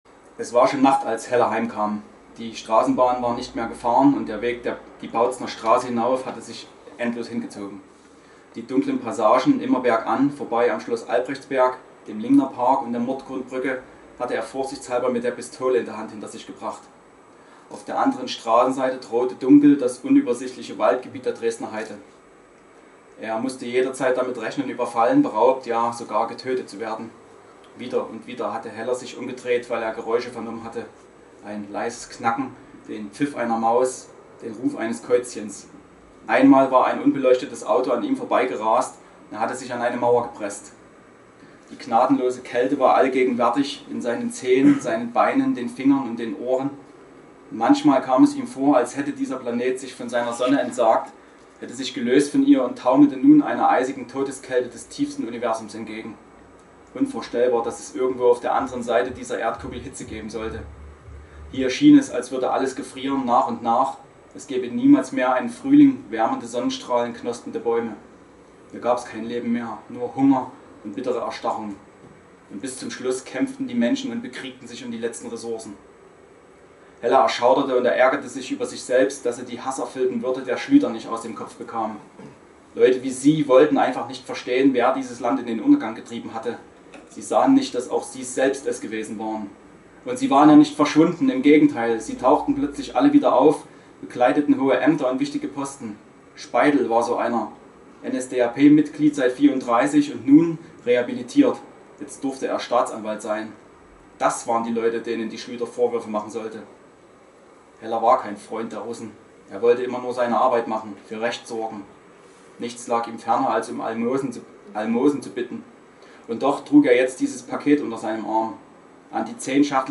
Autorenlesung